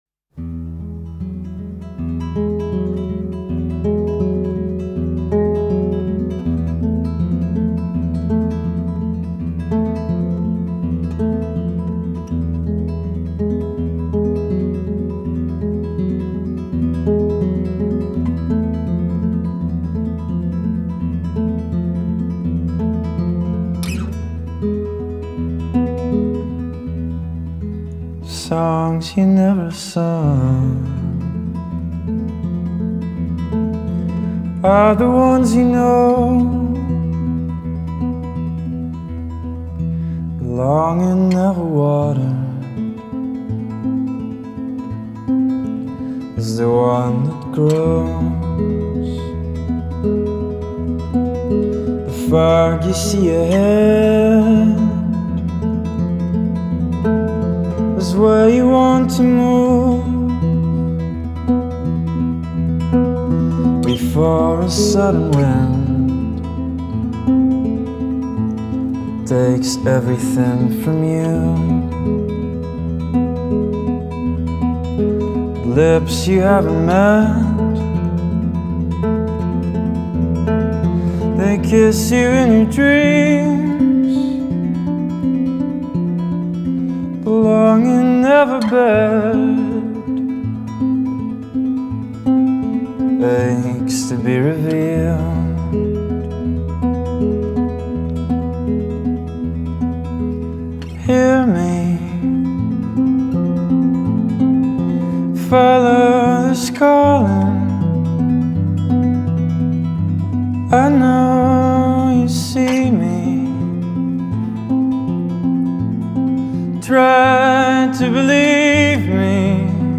Alternative Folk, Art Pop, Chamber Pop